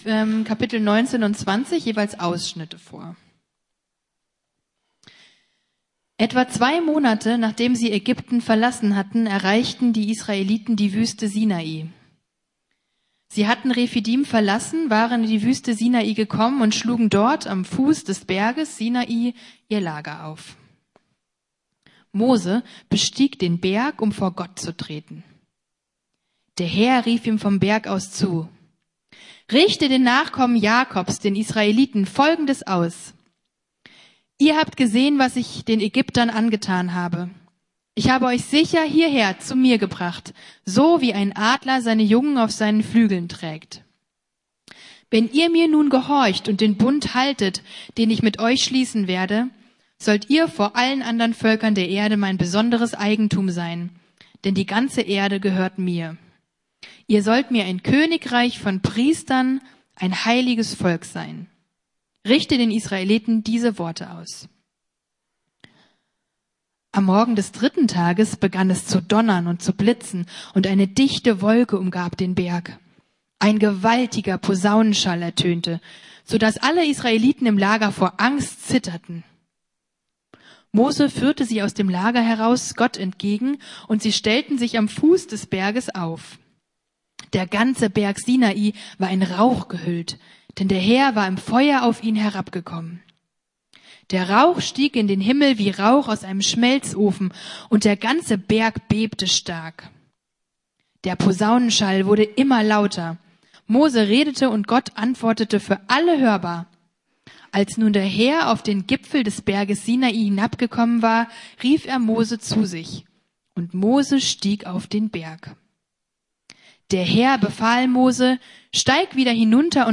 10 Worte des Lebens (Teil 1) ~ Predigten der LUKAS GEMEINDE Podcast